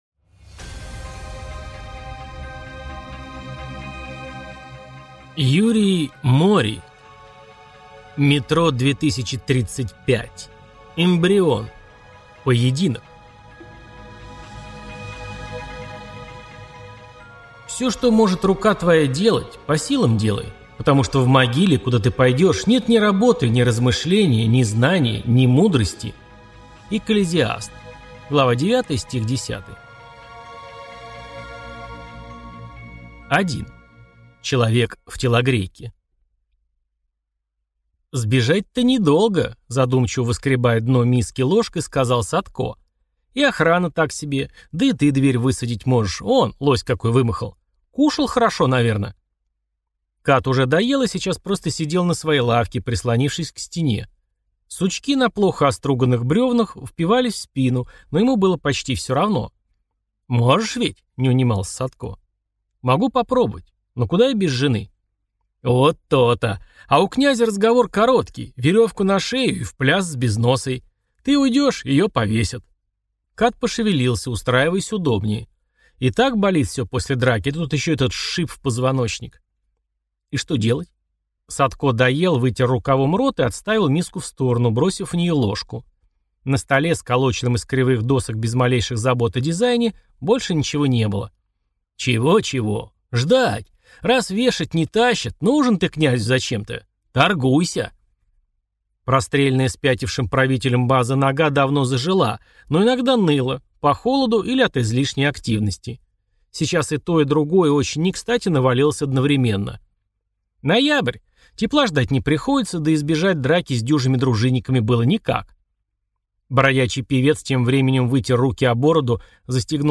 Аудиокнига Метро 2035: Эмбрион. Поединок | Библиотека аудиокниг